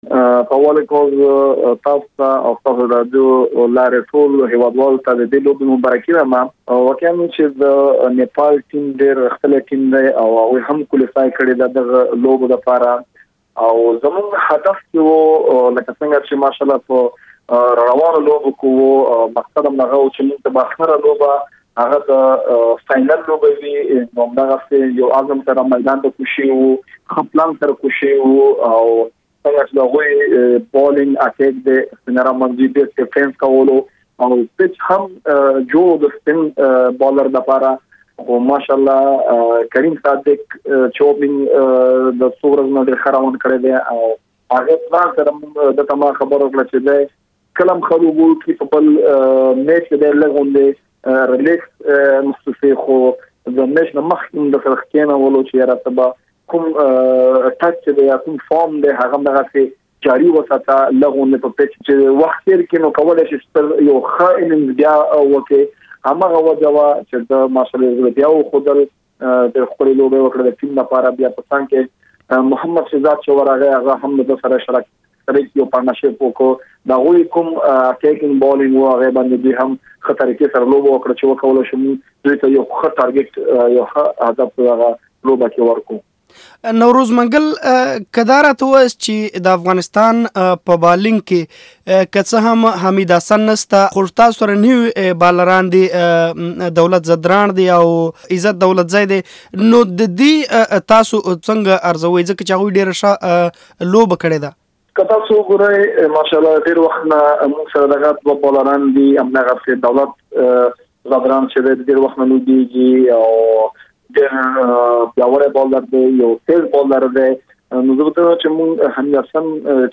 د افغان کرکټ ټيم پر بریاوو له نوروز منګل سره مرکه